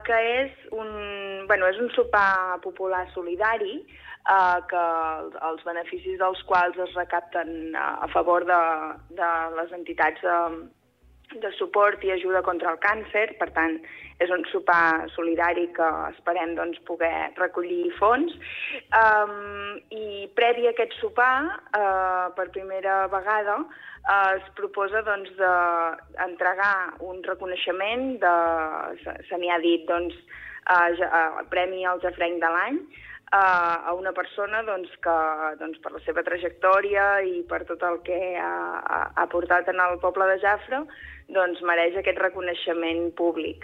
En una entrevista concedida al programa Supermatí, l’alcaldessa de Jafre, Èlia Bantí, ha detallat el programa d’actes de la Festa Major, que tindrà lloc aquest cap de setmana en honor a Sant Martí.